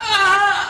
ouch.ogg